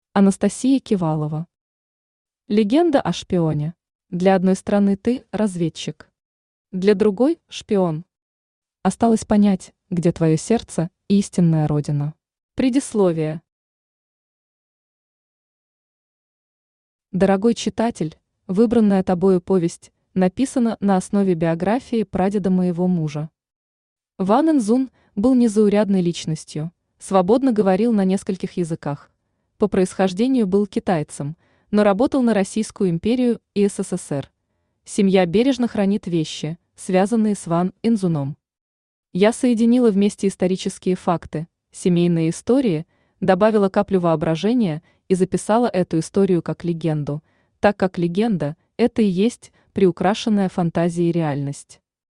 Аудиокнига Легенда о шпионе | Библиотека аудиокниг
Читает аудиокнигу Авточтец ЛитРес.